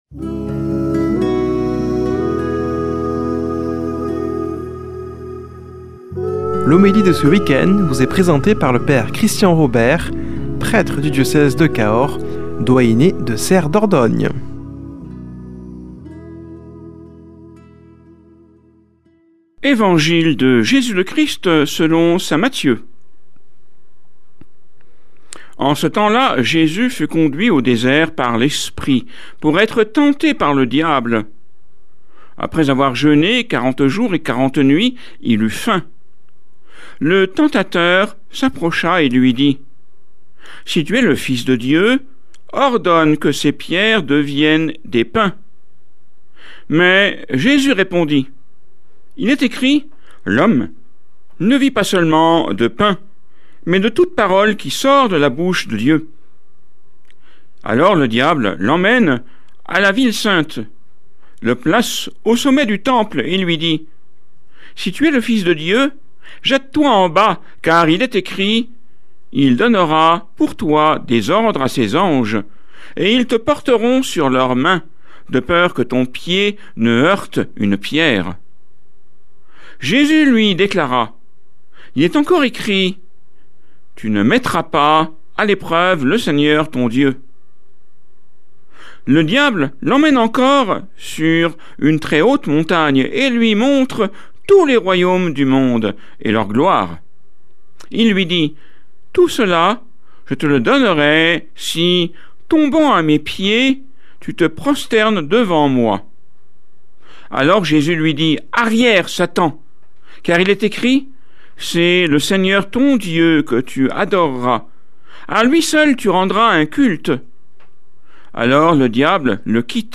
Homélie du 21 févr.